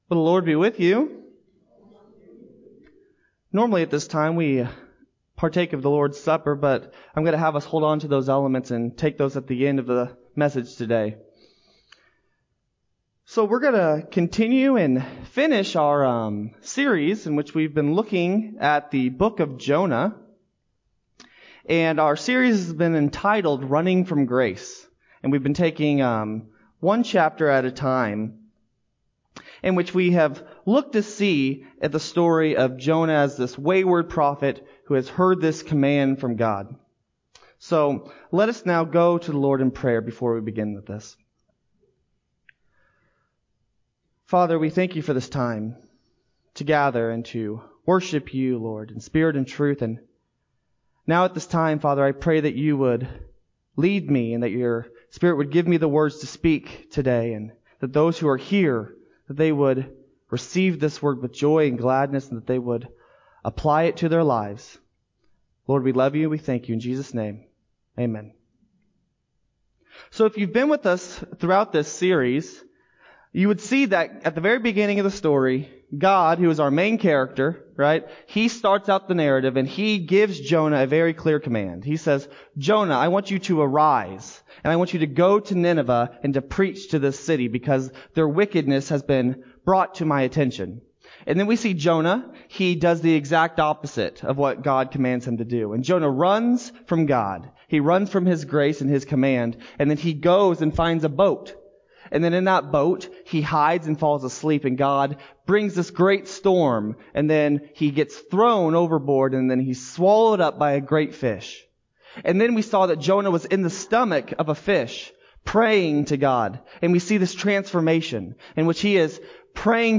9-13-20-Sermon-CD.mp3